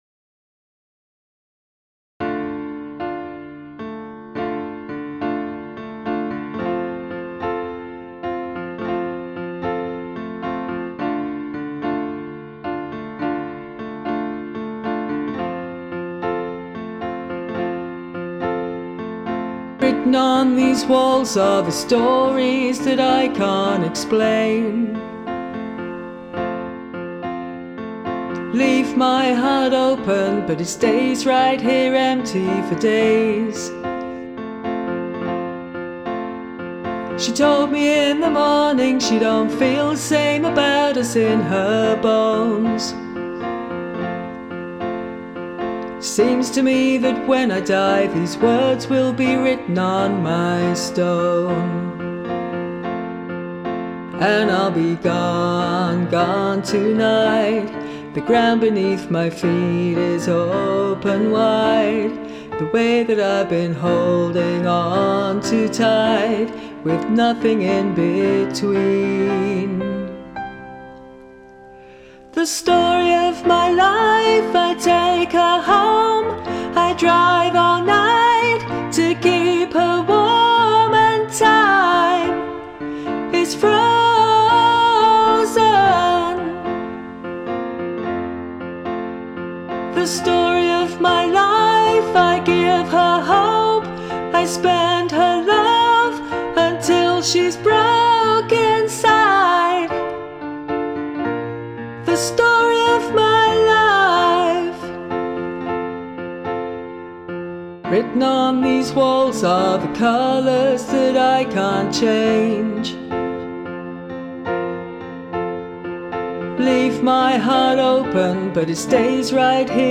It’s a great cover.